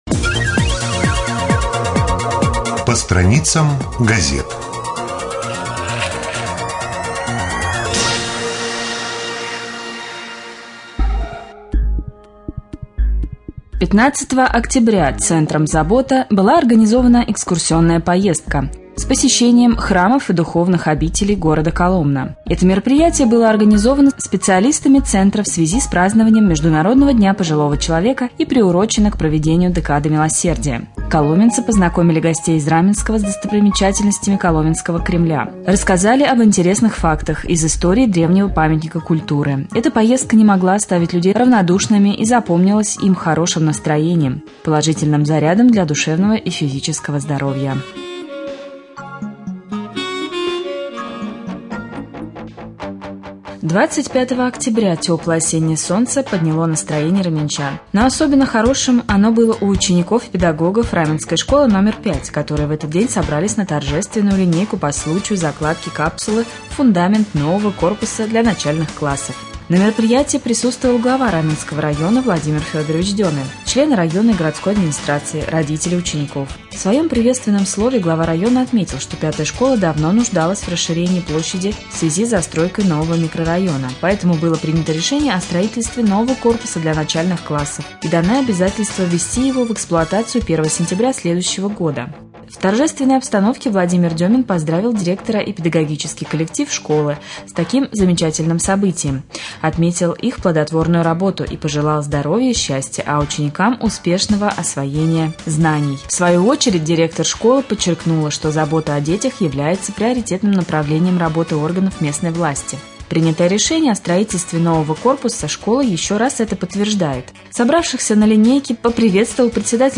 30.10.2013г. в эфире раменского радио